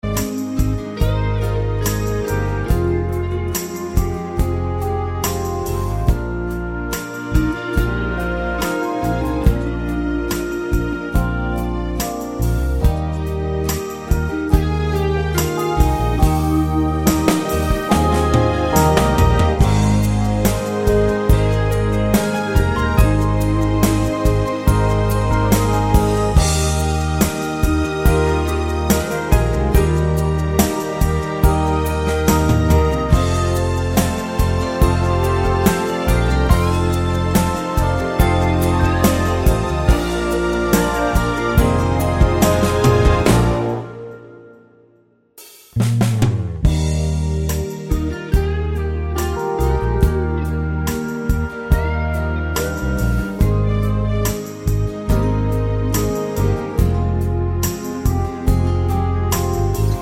no Backing Vocals Crooners 3:25 Buy £1.50